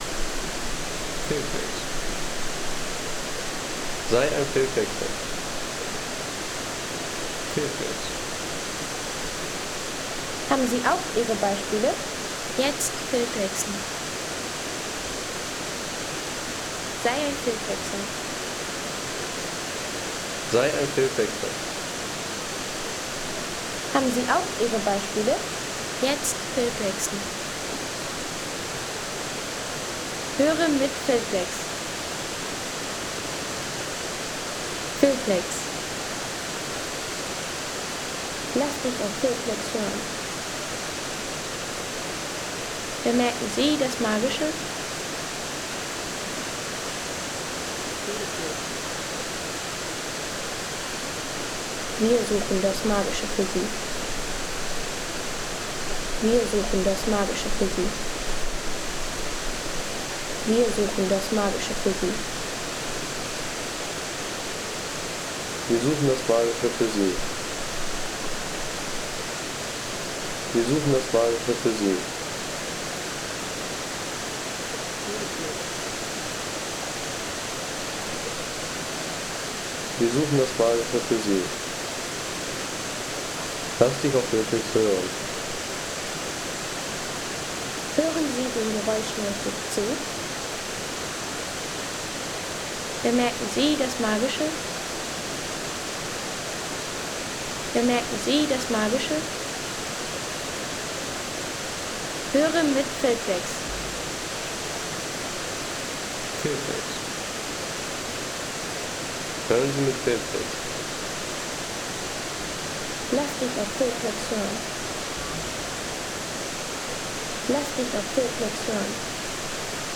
Wasserfall bei Goldbergmine
Wasserfall neben der berühmten Goldmine in Borca di Macugnaga.